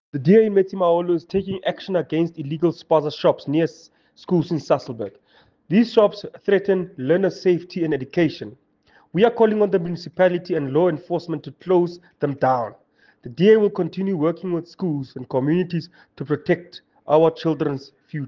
Afrikaans soundbites by Cllr Phemelo Tabile and